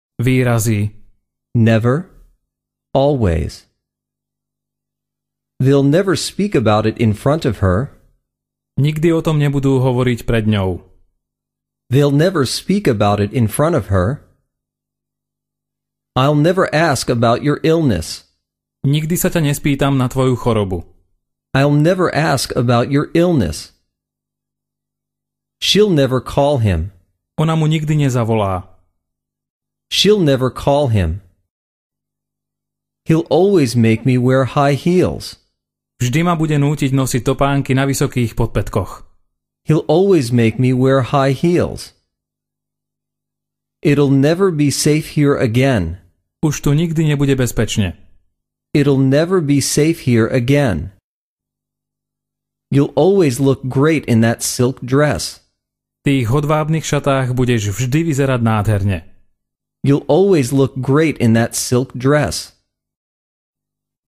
Ukázka z knihy
Audiokniha - Angličtina pre všetkých je určená pre samoukov. Každú vetu počujete najprv po anglicky, potom v slovenskom preklade a znovu v originálnom znení.
Príkladové vety nahovoril rodený Angličan.